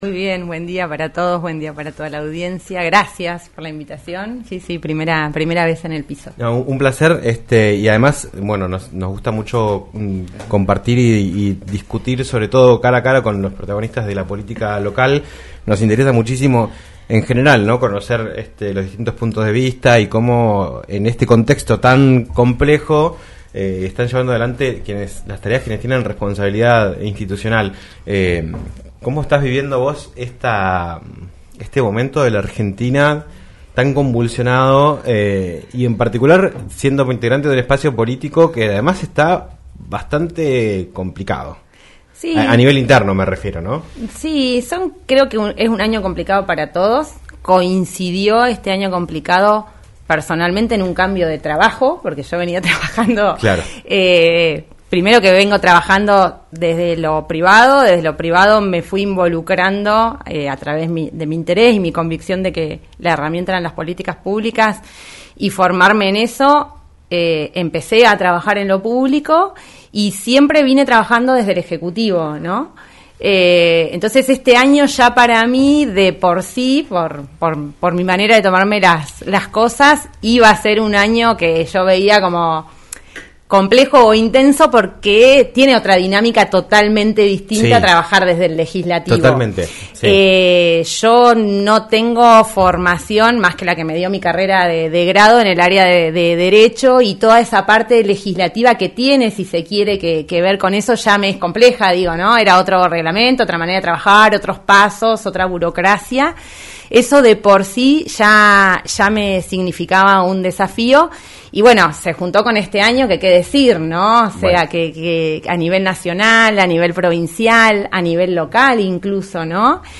Maite Luque, concejal de Comodoro Rivadavia por el bloque Arriba Chubut, visitó los estudios de LaCienPuntoUno para hablar en "Un Millón de Guanacos" sobre el trabajo del Concejo Deliberante en este año.